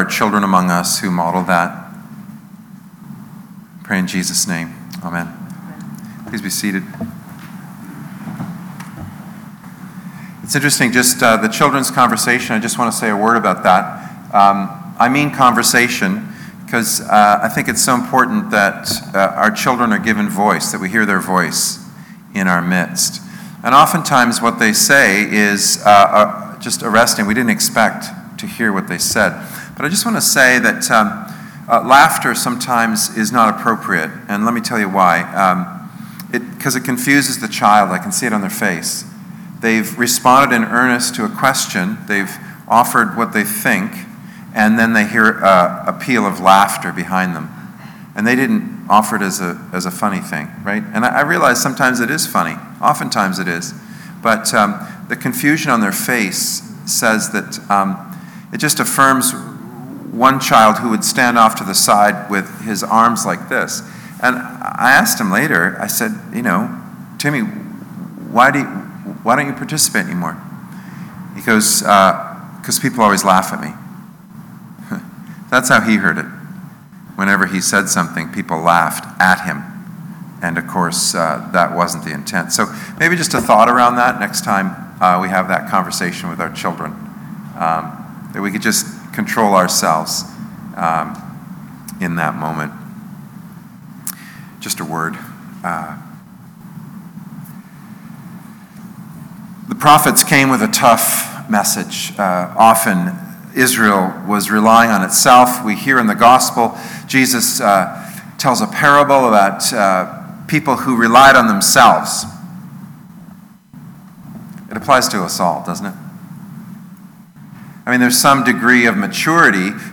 Sermons | St. Cuthbert Anglican Church
10:00 am Service